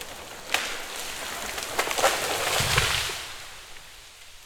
Tree Falling
tree-falling-1.ogg